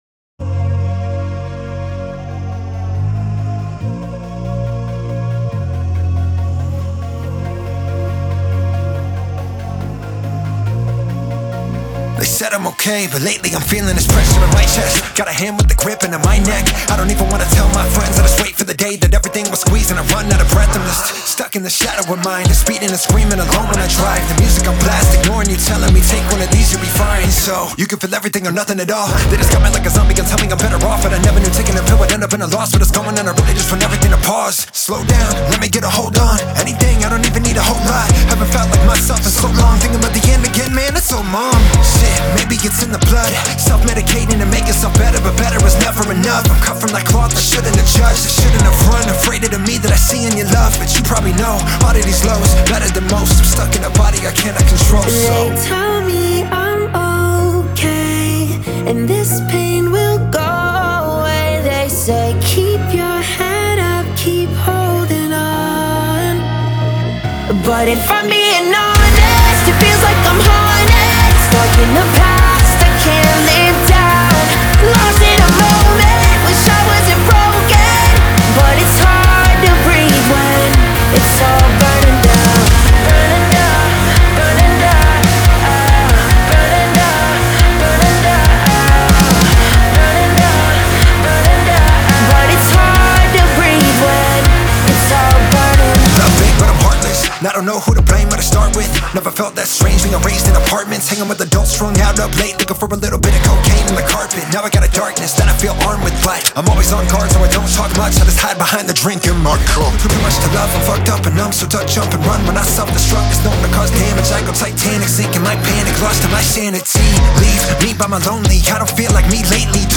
• Жанр: Rap, Rock